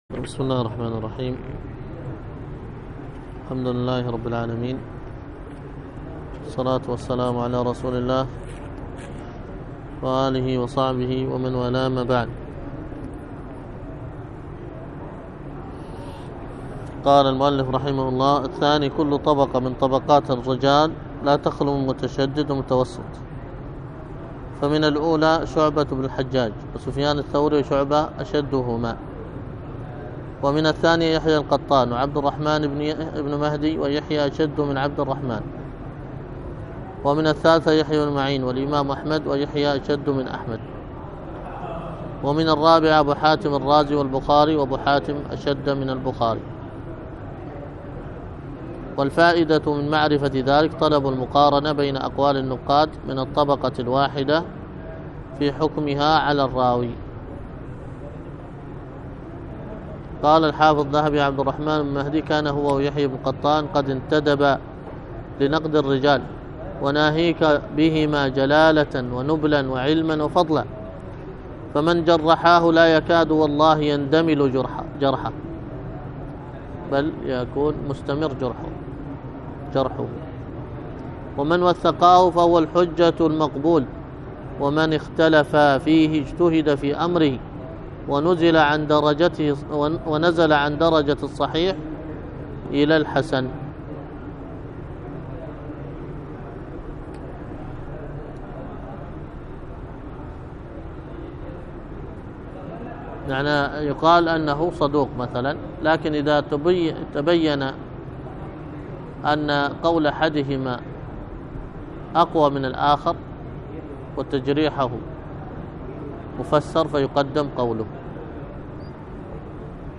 الدرس في الصحيح المسند مما ليس في الصحيحين 2، ألقاها